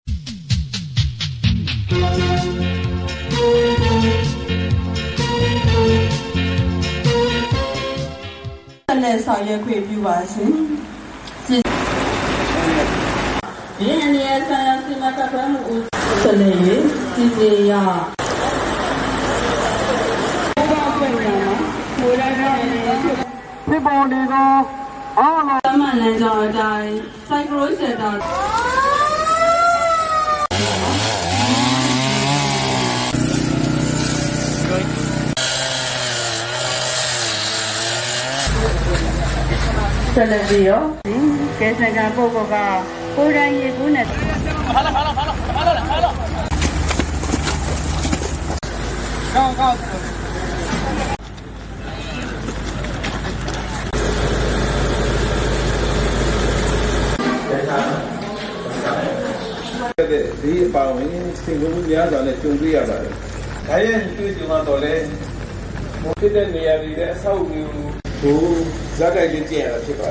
တနင်္သာရီတိုင်းဒေသကြီးဝန်ကြီးချုပ်ဦးမြတ်ကို မုန်တိုင်းဘေးအန္တရာယ်တုံ့ပြန်ဆောင်ရွက်ရေး ဇာတ်တိုက်လေ့ကျင့်ခြင်း အခမ်းအနားသို့တက်ရောက် ထားဝယ် ဇူလိုင် ၁၇